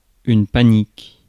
Ääntäminen
Synonyymit peur fuite frayeur Ääntäminen France: IPA: /pa.nik/ Haettu sana löytyi näillä lähdekielillä: ranska Käännös Ääninäyte Substantiivit 1. panic UK US Adjektiivit 2. panicked Suku: f .